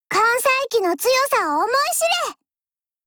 Cv-40702_warcry.mp3